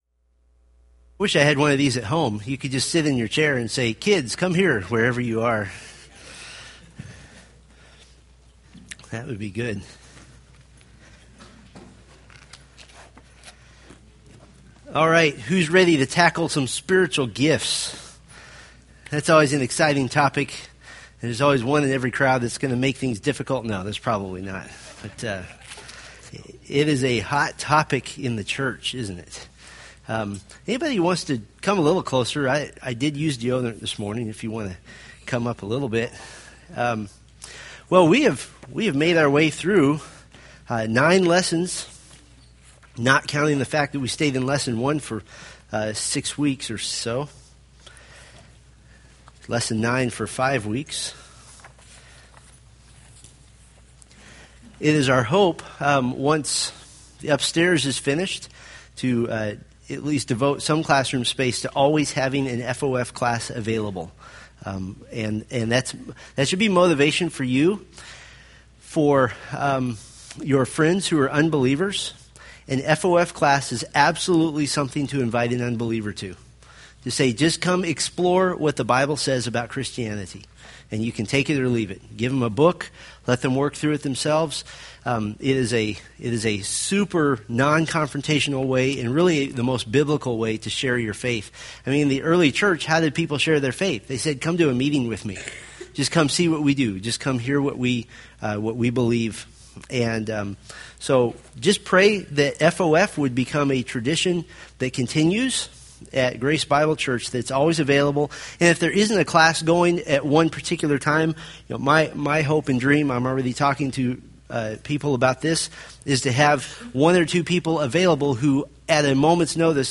Date: Sep 15, 2013 Series: Fundamentals of the Faith Grouping: Sunday School (Adult) More: Download MP3